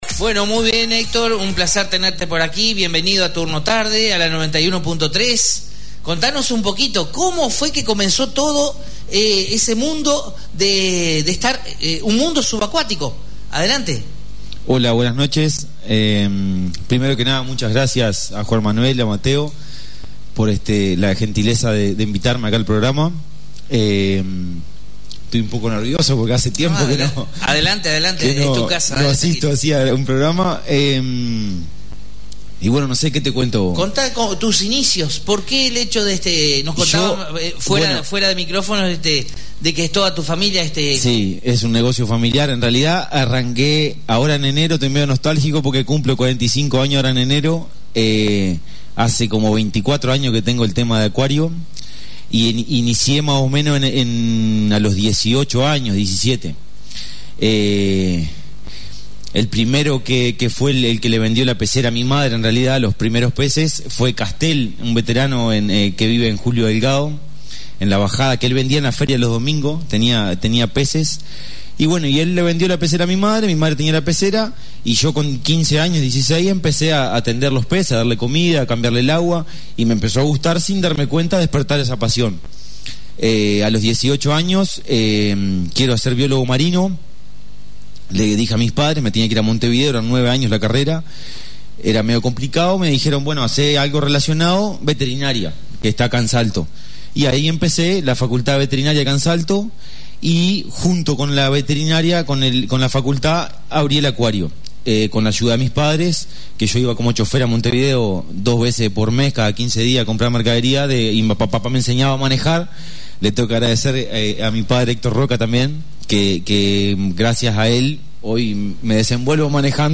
Recibimos en estudios